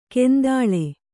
♪ kendāḷe